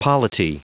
Prononciation du mot polity en anglais (fichier audio)
Prononciation du mot : polity